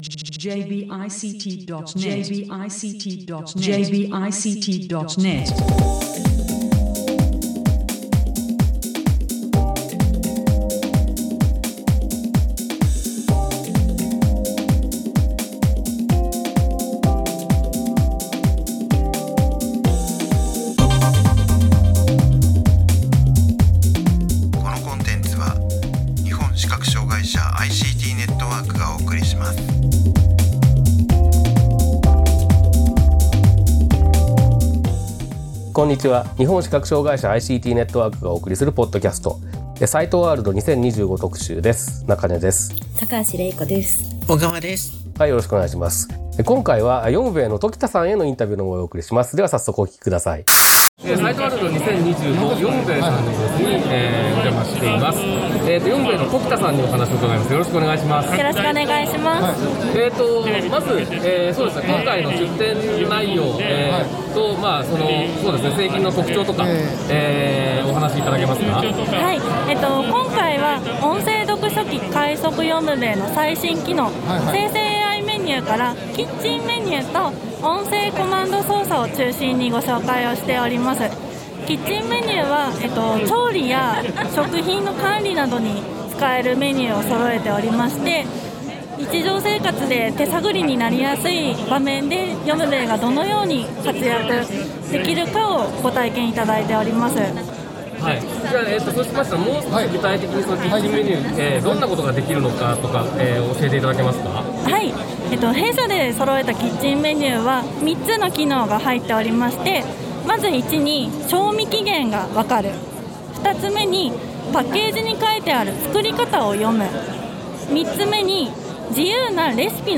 株式会社よむべえのインタビューをお送りします。音声・拡大読書機の快速よむべえシリーズの製品に搭載された、生成AIを活用した最新機能のお話を中心に伺っています。
サイトワールド2025特集第13回：株式会社よむべえ ファイルのダウンロード: サイトワールド2025特集第13回：株式会社よむべえ 2025年10月16日から18日にかけて東京都内で行われたサイトワールド2025に関連する取材の模様をお送りしているサイトワールド2025特集の第13回です。